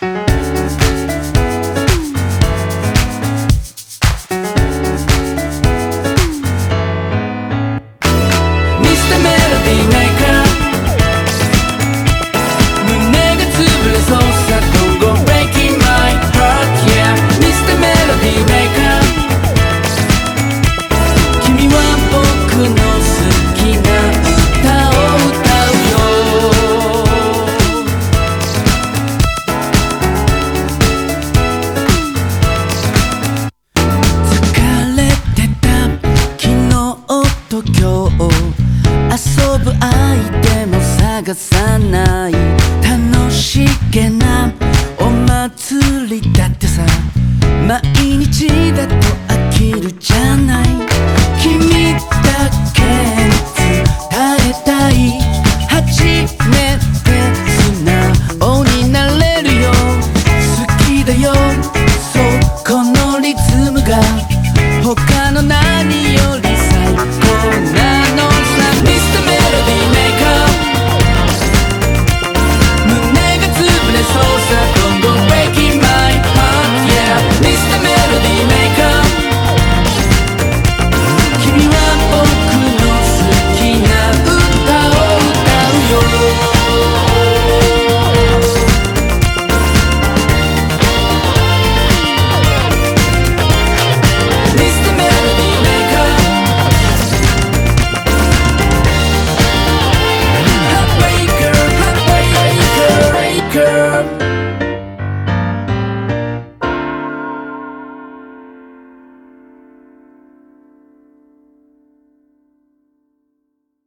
BPM112
Audio QualityMusic Cut